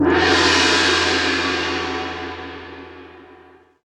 Gong
When you hit a chau gong, you hear a crashing sound that is sure to mesmerize.
Gong.mp3